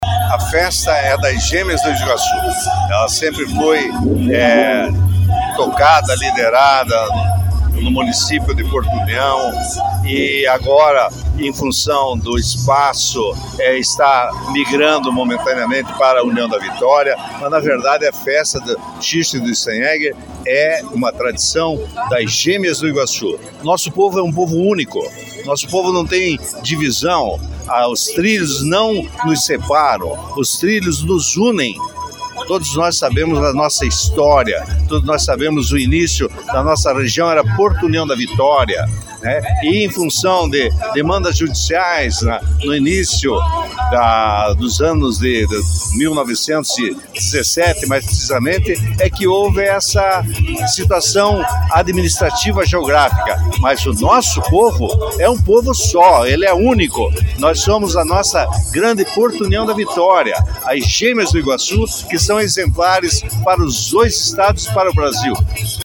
A solenidade de abertura ocorreu em frente ao Clube Apolo, reunindo autoridades, organizadores e a comunidade local.
O prefeito de União da Vitória também comemorou a escolha do novo espaço, ressaltando que a festa continua sendo de toda a população das duas cidades://